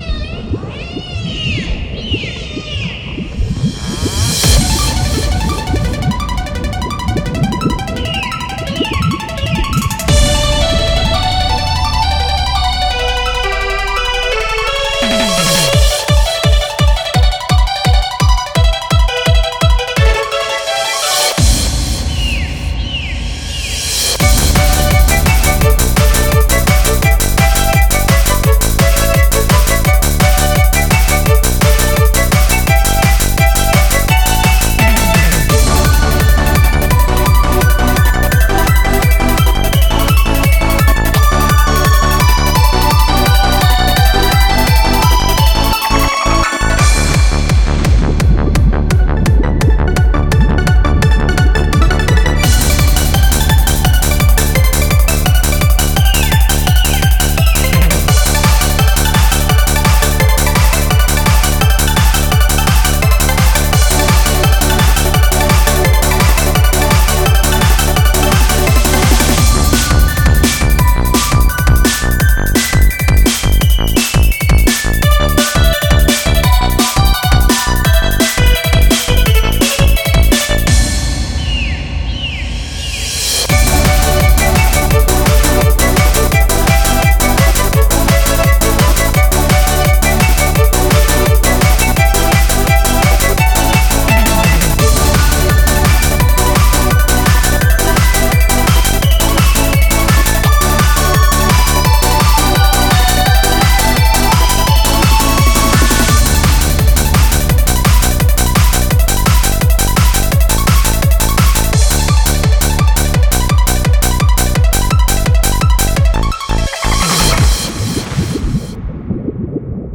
BPM170
HAPPY HARDCORE
This track has been cut from the original format